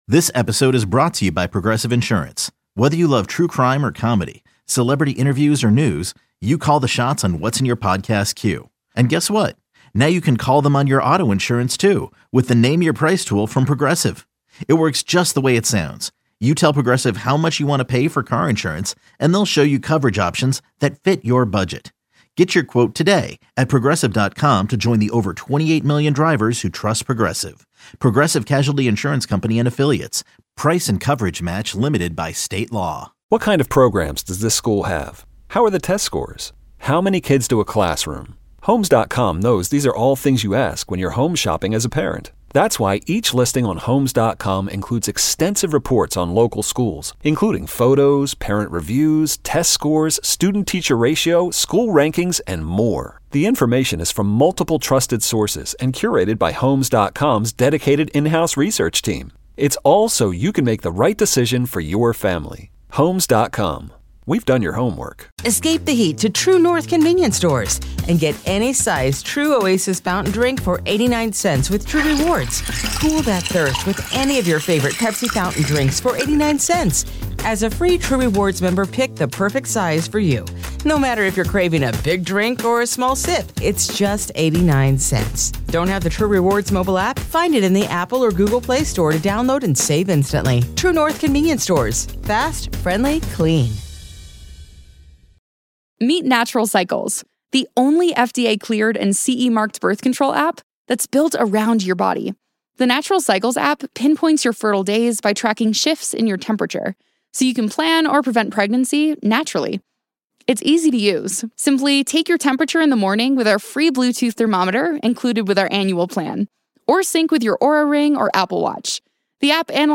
Best of WGR Interviews: June 30 - July 4
The best interviews this week from WGR.